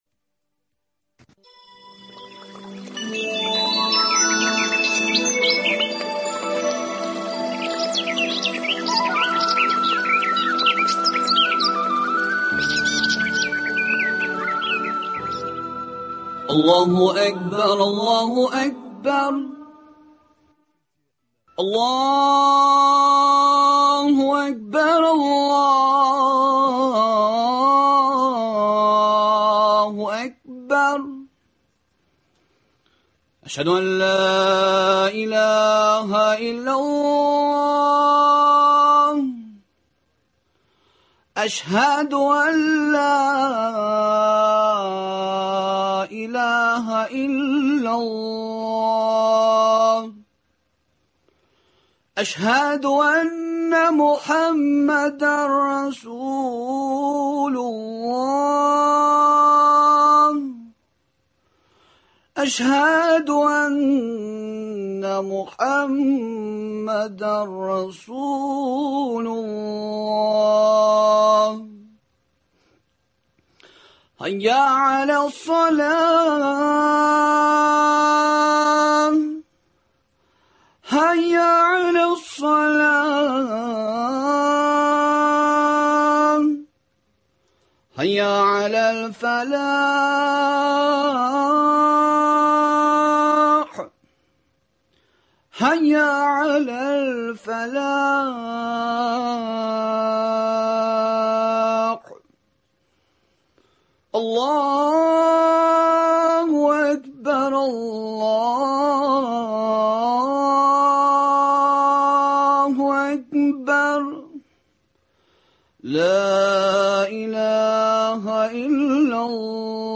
әңгәмәдә ишетерсез.